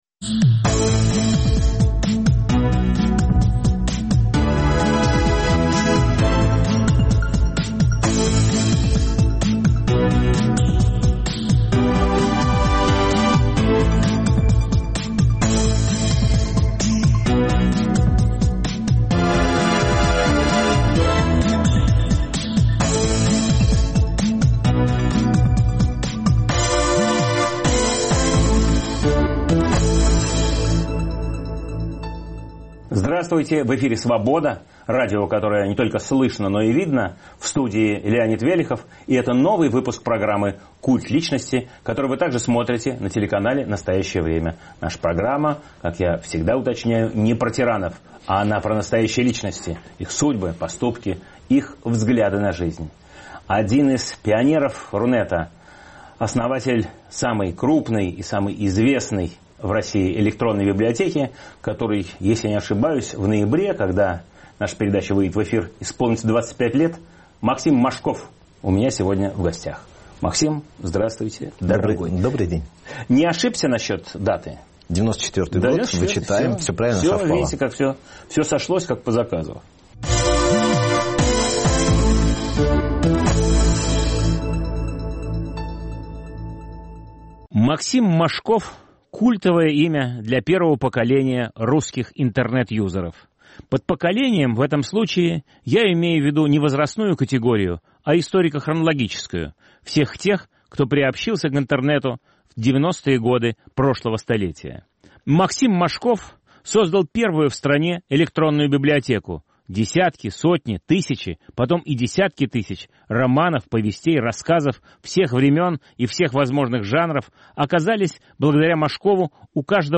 В студии – один из пионеров Рунета, 25 лет назад создавший первую в России электронную библиотеку.